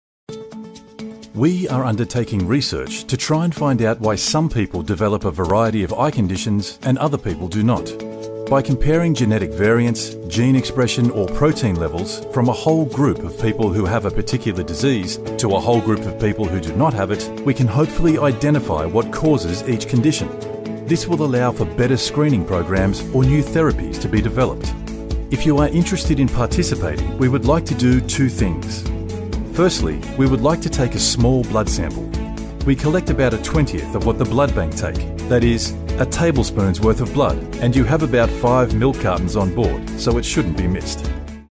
Medical Narration
Rode NT-1, Sennheiser 416 microphones